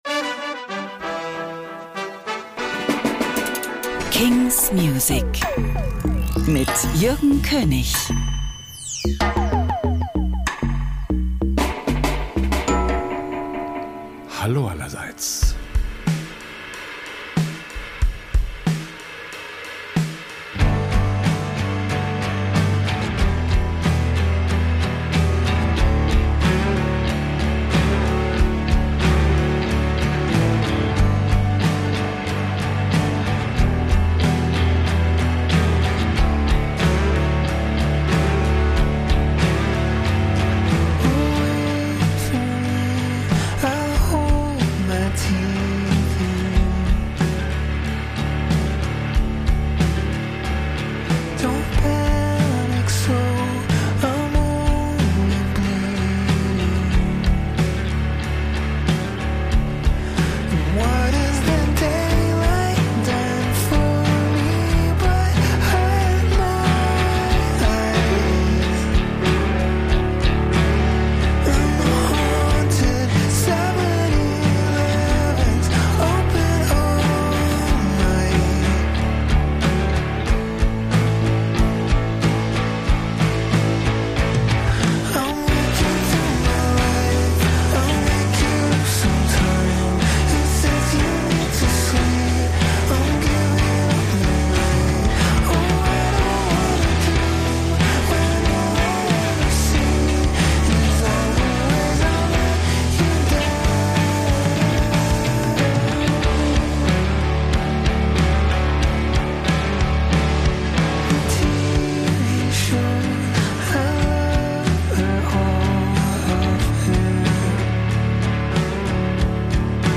great, new indie & alternative releases.